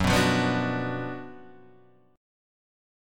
F#M7sus4#5 chord